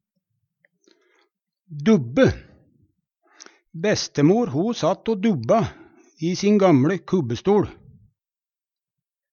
DIALEKTORD PÅ NORMERT NORSK dubbe sove, småsove Infinitiv Presens Preteritum Perfektum dubbe dubba dubba dubba Eksempel på bruk Bestemor satt o dubba i sin gamLe kubbestoL Hør på dette ordet Ordklasse: Verb Attende til søk